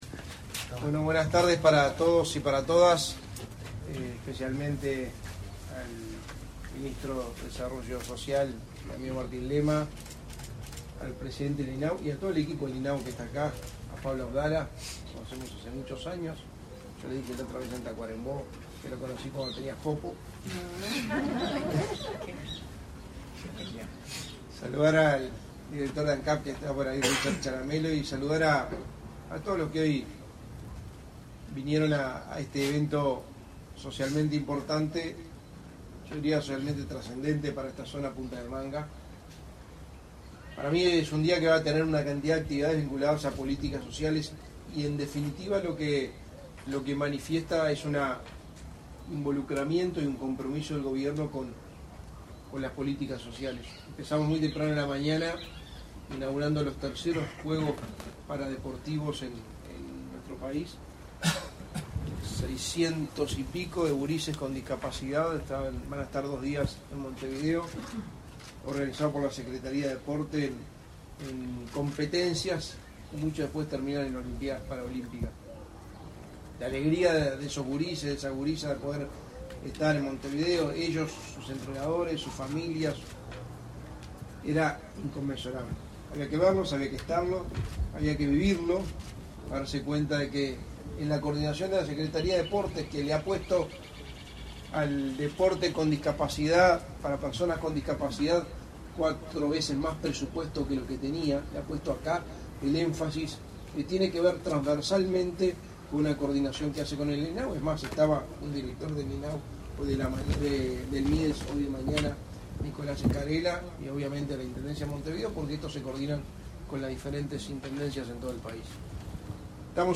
Palabras del secretario de la Presidencia, Álvaro Delgado
Con la presencia del secretario de la Presidencia, Álvaro Delgado, el Instituto del Niño y el Adolescente del Uruguay (INAU) inauguró, este 6 de
Delgado acto.mp3